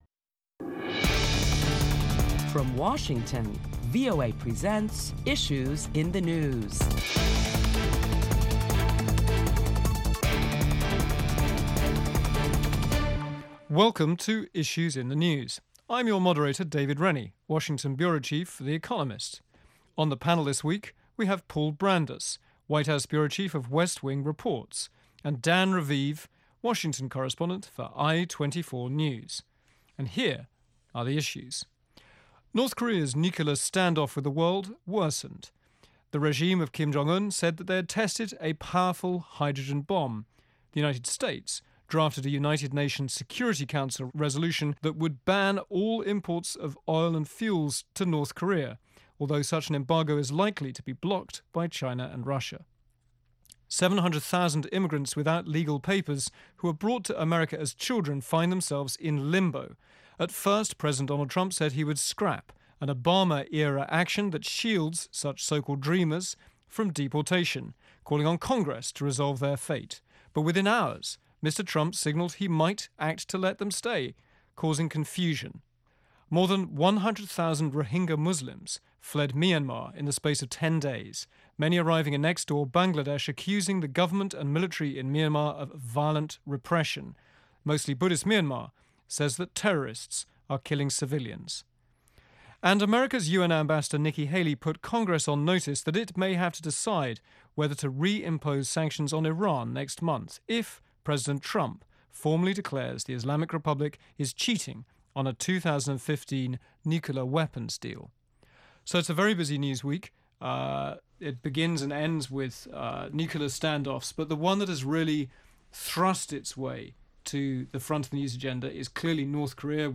This week on Issues in the News, prominent Washington correspondents discuss topics making headlines around the world, including North Korea’s continued missile testing, and the plight of tens of thousands of Rohingya refugees displaced by violence in Myanmar.